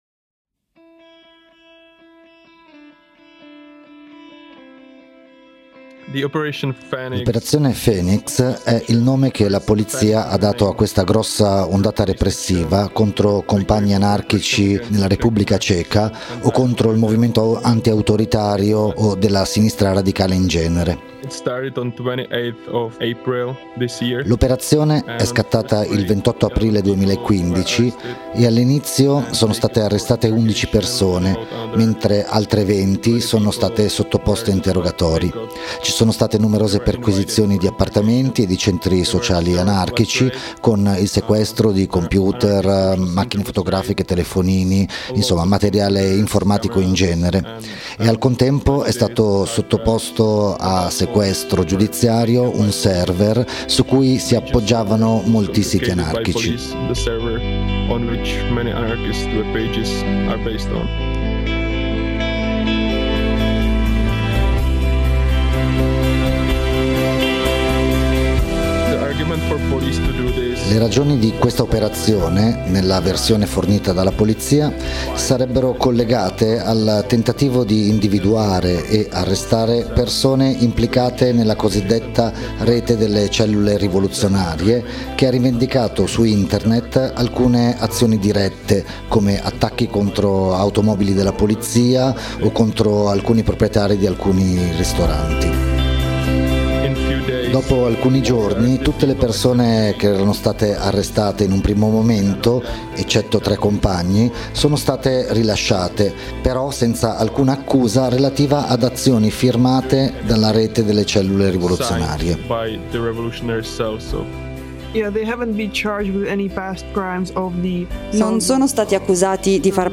Una vicenda segnata dall’inquietante ruolo svolto da alcuni agenti infiltrati. Un compagno e una compagna ci raccontano alcuni dettagli di questa operazione, con l’invito a sostenere gli arrestati, con benefit, azioni informative, lettere o quant’altro.